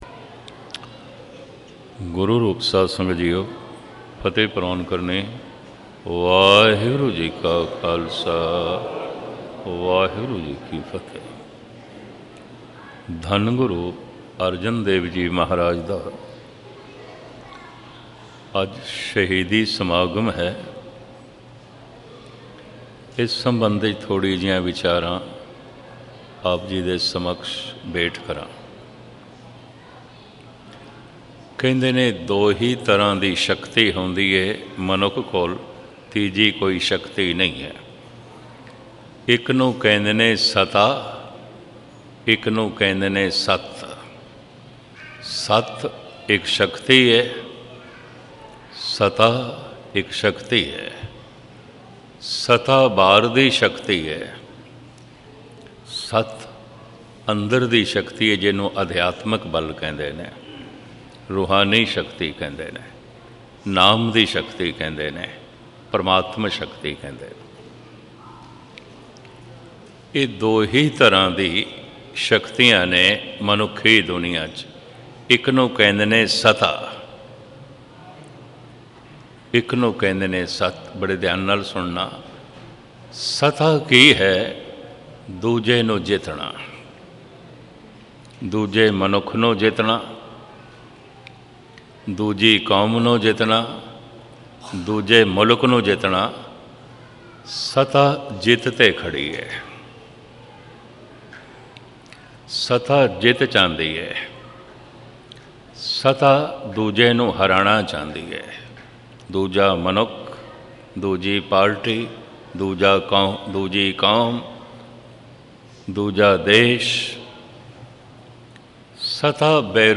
Katha